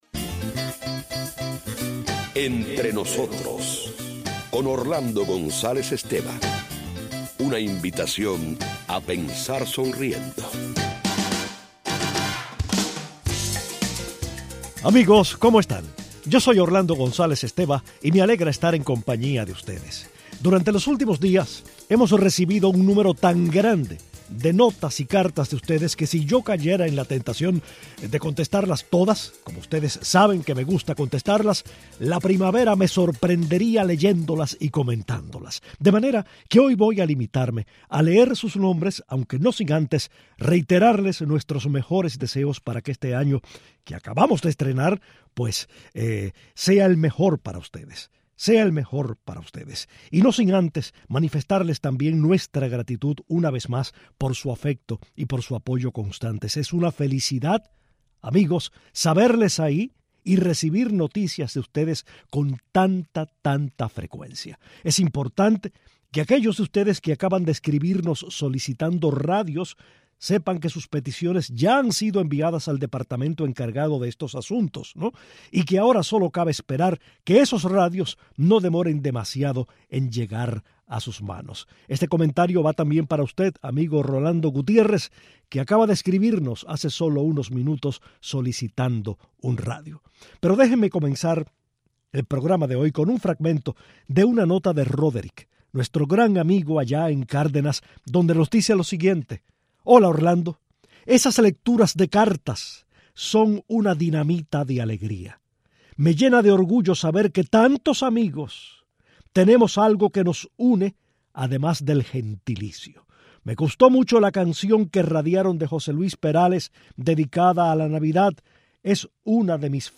Continuamos leyendo los mensajes recibidos y conversando al aire con los oyentes que nos llaman para felicitarnos.